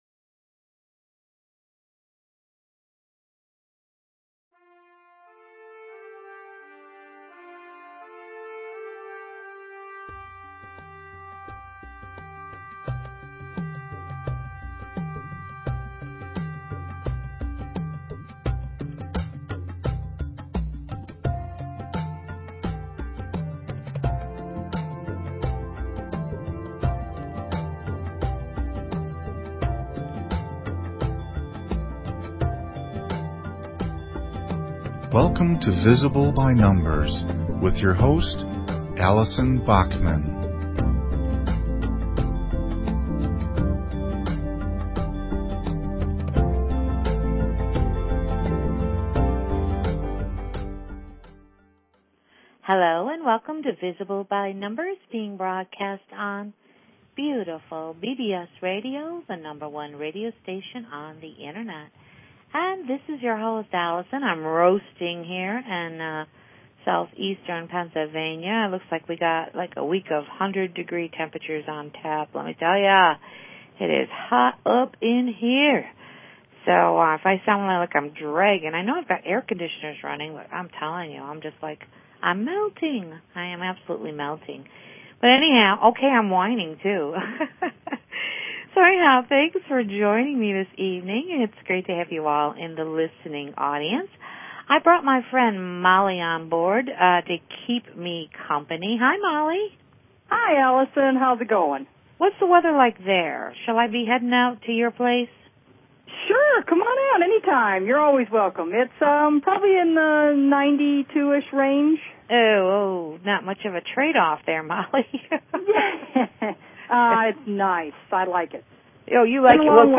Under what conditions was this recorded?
Live-On-Air Readings